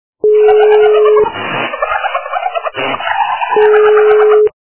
» Звуки » Смешные » Смех - Смех
При прослушивании Смех - Смех качество понижено и присутствуют гудки.
Звук Смех - Смех